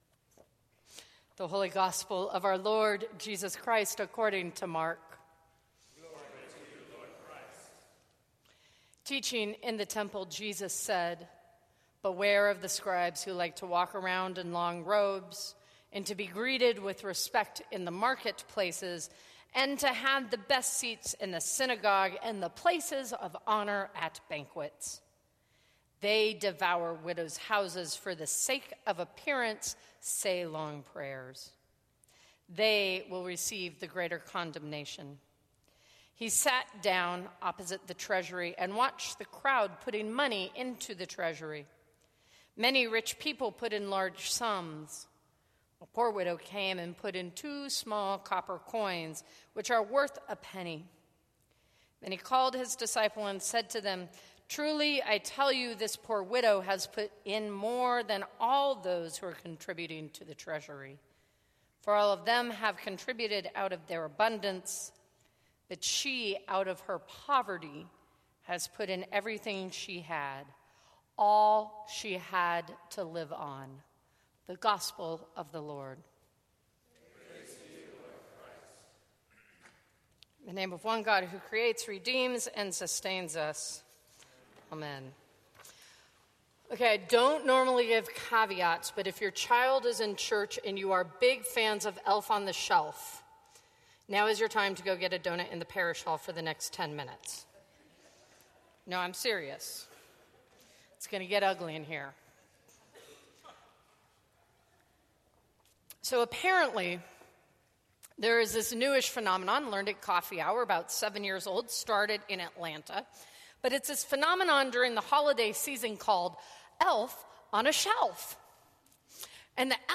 Sermons from St. Cross Episcopal Church Elf on a Shelf Dec 09 2015 | 00:13:27 1x 00:00 / 00:13:27 Subscribe Share Apple Podcasts Spotify Overcast RSS Feed Share Link Embed